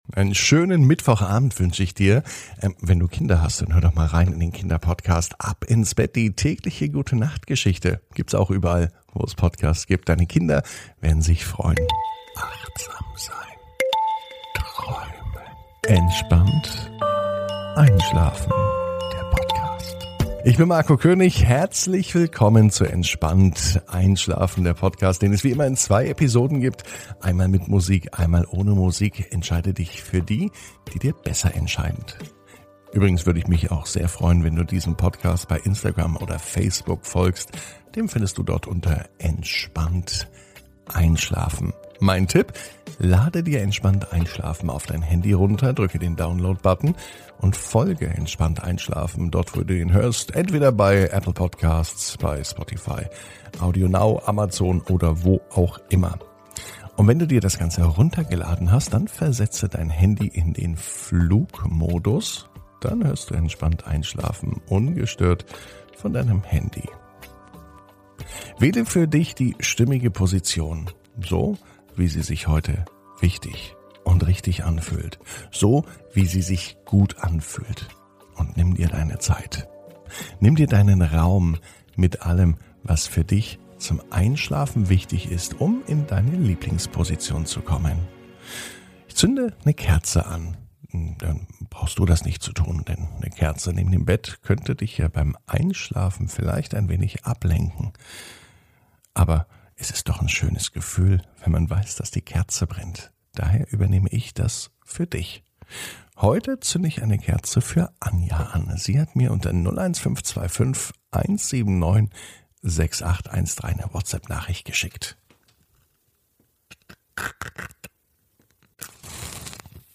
(Ohne Musik) Entspannt einschlafen am Mittwoch, 02.06.21 ~ Entspannt einschlafen - Meditation & Achtsamkeit für die Nacht Podcast